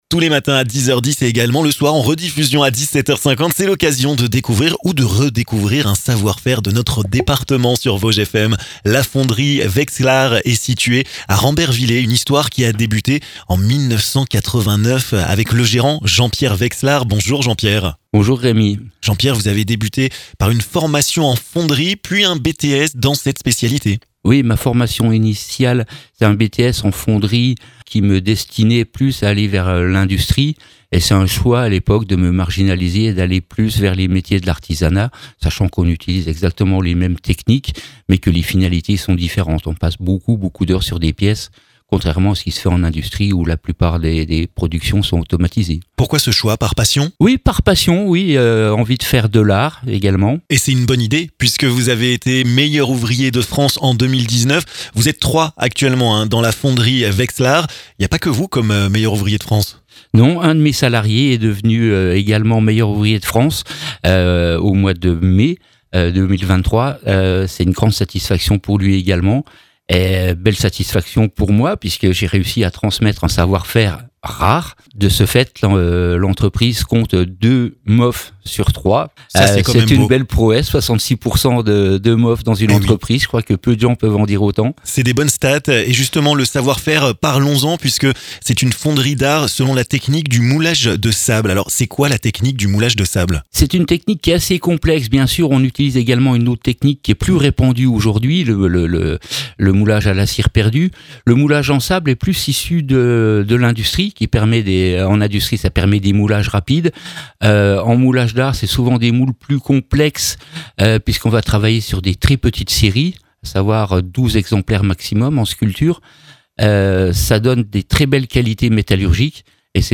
Il vous présente son savoir-faire dans cette interview de "on sait le faire dans les Vosges"!